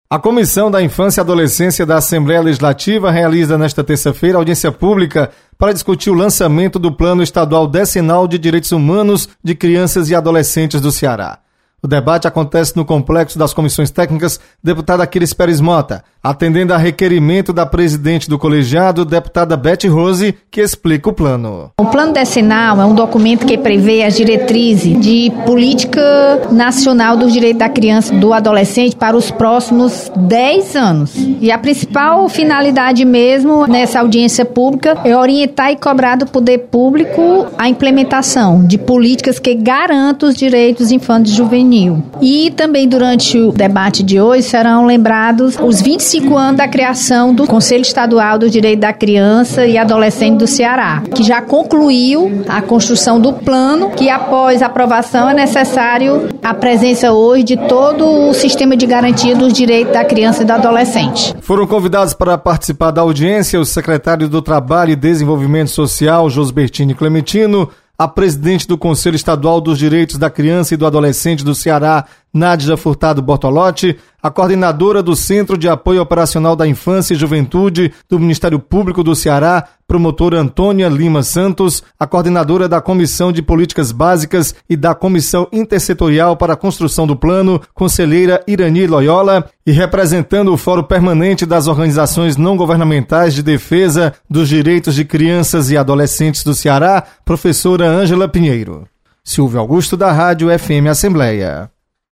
Comissão da Infância e Adolescência vai discutir Plano Estadual Decenal de Direitos de Crianças e Adolescentes. Repórter